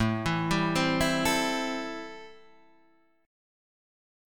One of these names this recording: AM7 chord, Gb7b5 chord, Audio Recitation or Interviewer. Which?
AM7 chord